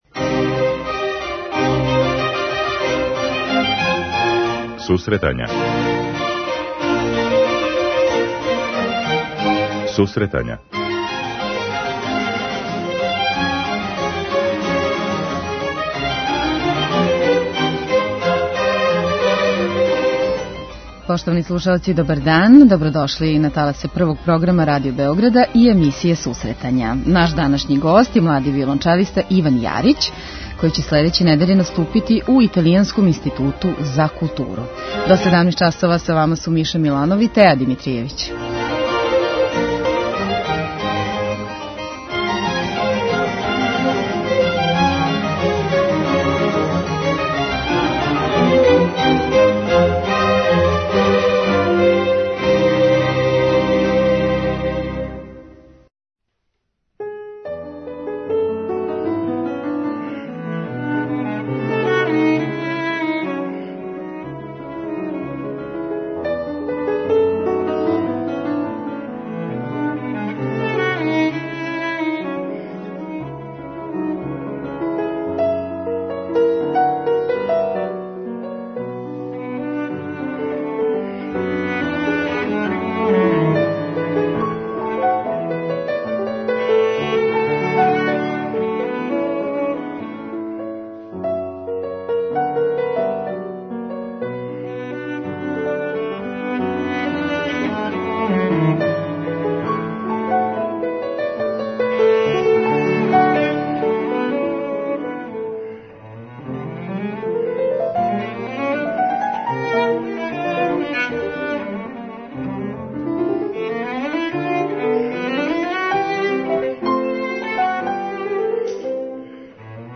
преузми : 10.75 MB Сусретања Autor: Музичка редакција Емисија за оне који воле уметничку музику.